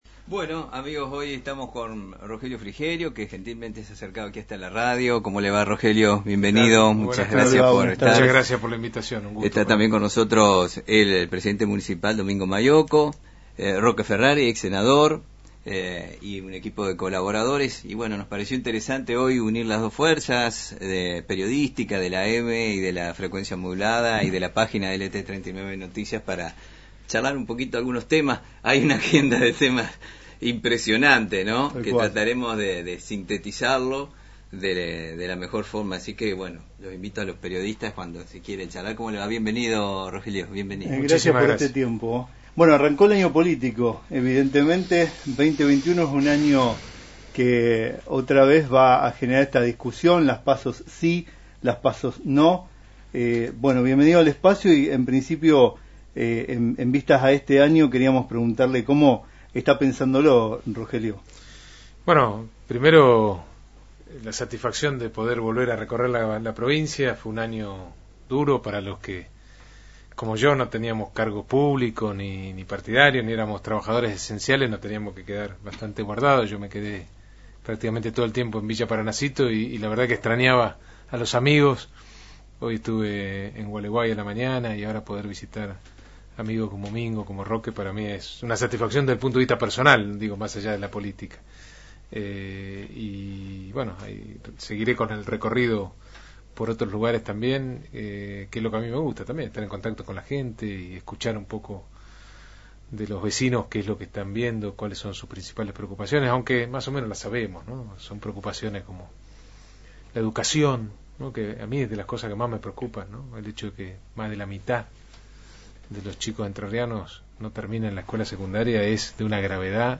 Rogelio Frigerio en LT39 AM 980, en diálogo